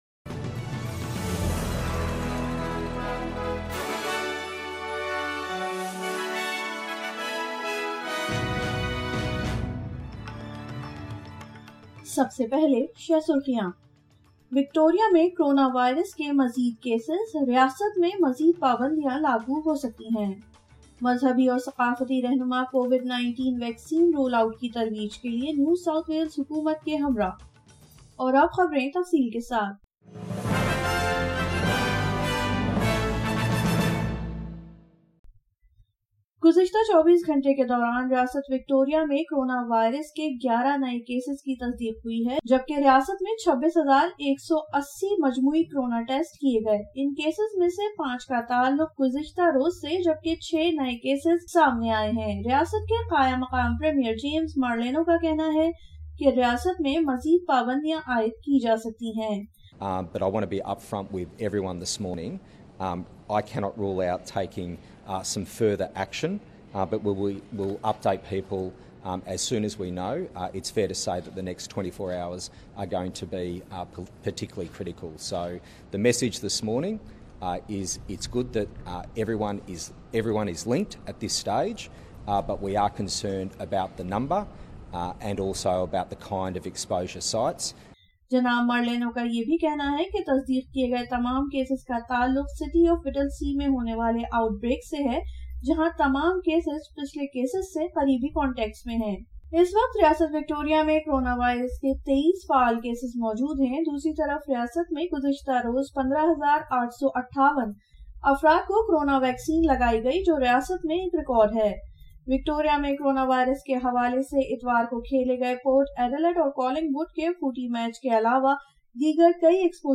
SBS Urdu News 26 May 2021